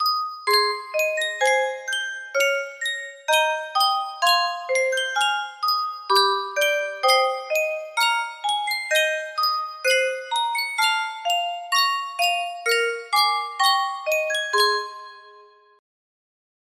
Sankyo Music Box - I Heard the Bells on Christmas Day -C music box melody
Full range 60